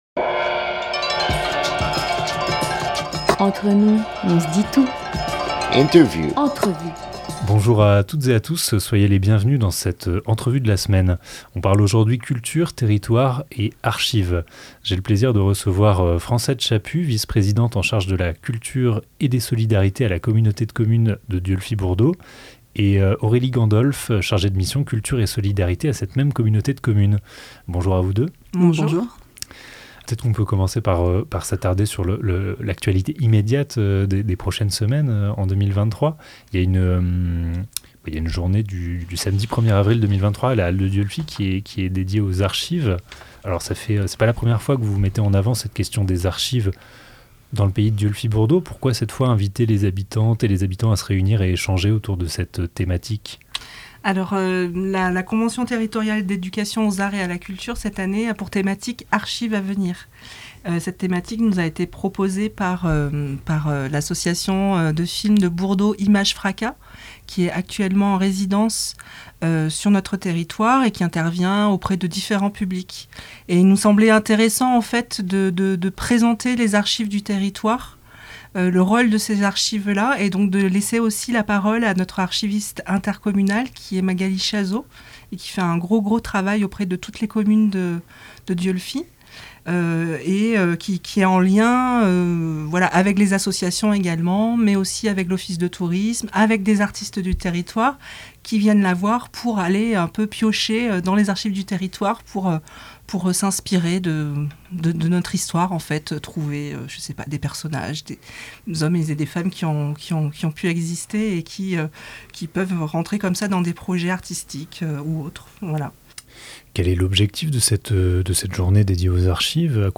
26 mars 2023 20:12 | culture, Interview